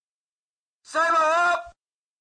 322装填弹药音效02-59小钢炮
修改说明 装填弹药音效02款
322装填弹药音效02.mp3